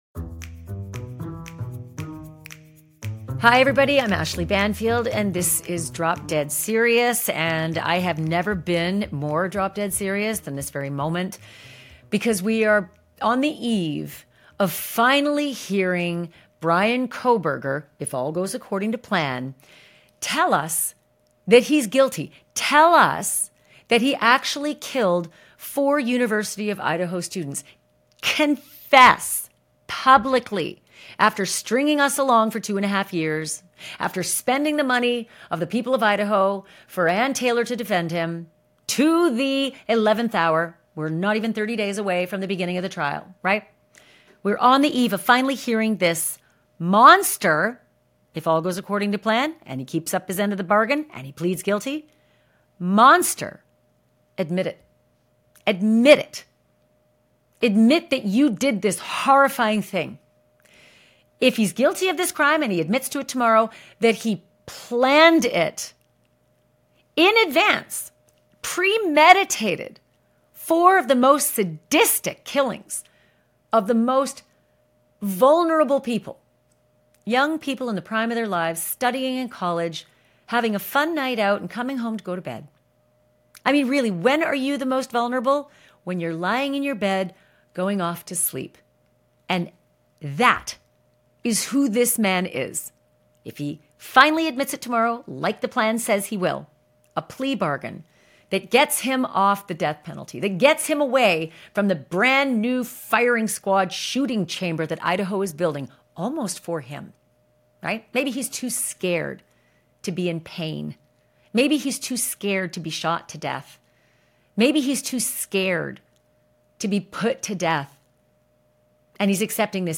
Megyn Kelly joins Ashleigh Banfield for an explosive conversation about the courtroom twist that sent shockwaves through Idaho and beyond.